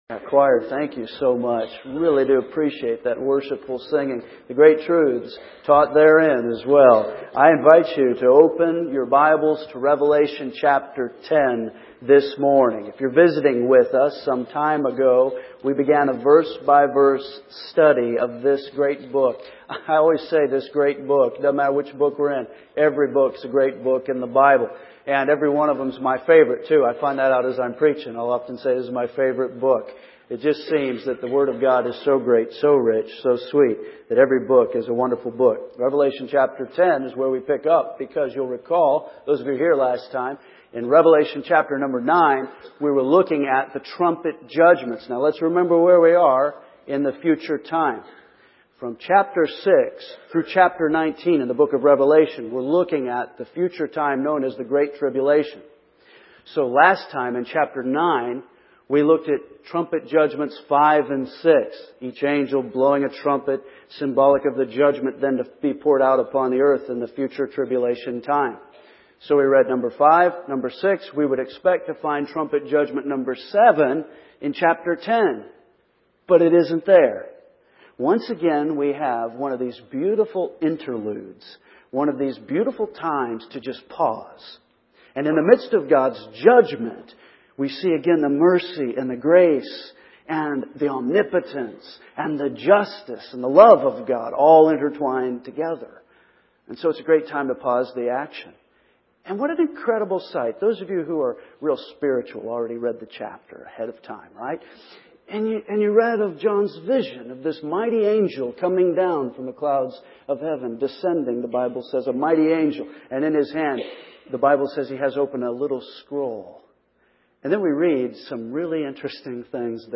We are continuing our verse-by-verse exposition of the book of Revelation.